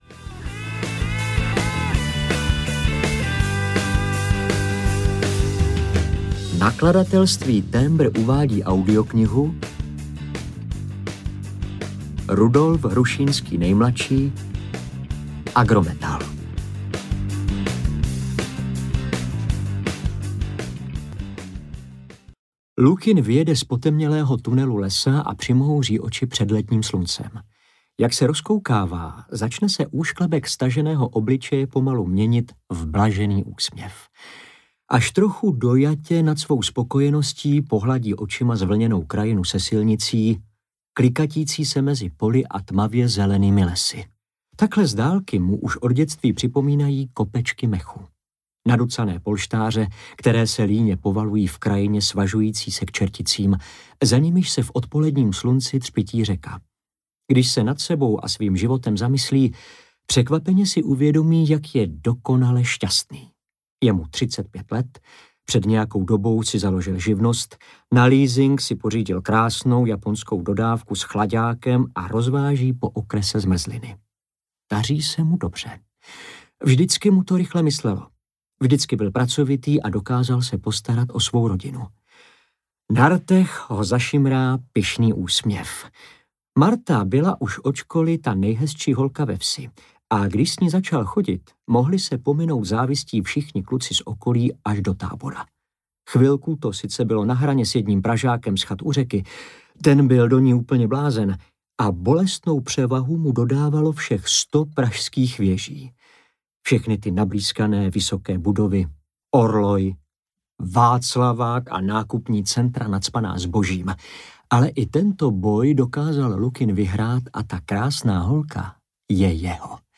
Agrometal audiokniha
Ukázka z knihy
• InterpretJaroslav Plesl